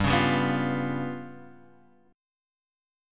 G7b5 chord